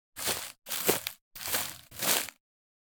footsteps-sequence-outdoors-002.ogg